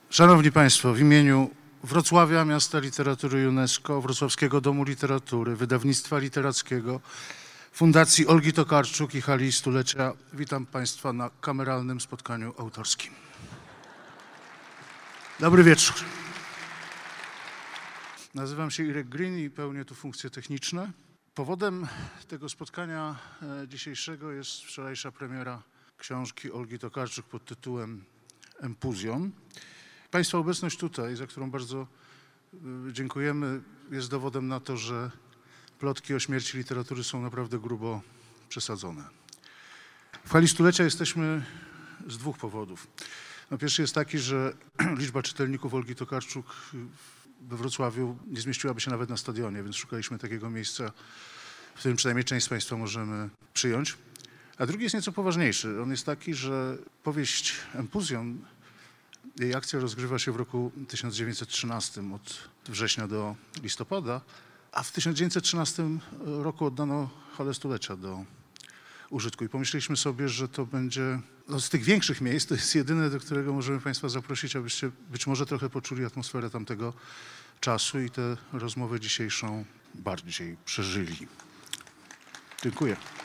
Wczoraj, 2 czerwca w Hali Stulecia miała miejsce oficjalna premiera nowej autorki Czułego narratora, Olgi Tokarczuk.